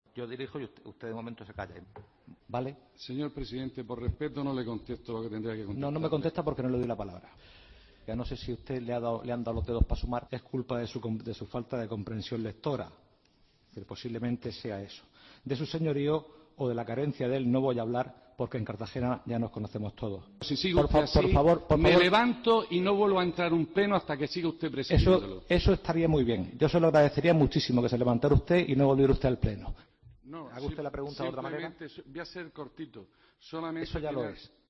Escucha al alcalde de Cartagena impedir que hable el concejal del PP